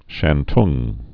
(shăn-tŭng)